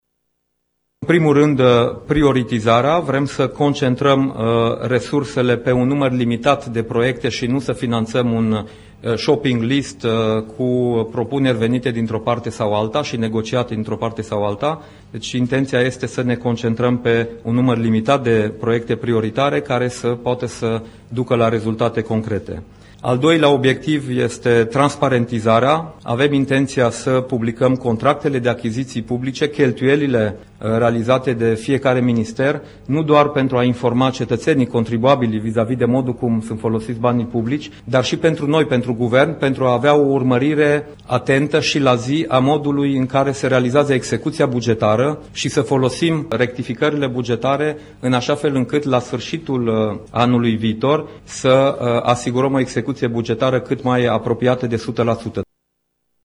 Executivul a aprobat, azi, proiectul de lege privind bugetul de stat şi pe cel al asigurărilor sociale de stat pe anul 2016, cele două acte normative fiind trimise Parlamentului pentru dezbatere. În cadrul unei conferințe de presă, premierul Dacian Cioloș a precizat că bugetul a fost construit ținându-se seama de un prag maxim de deficit de 3%, convenit la nivelul UE.